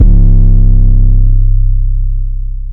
808s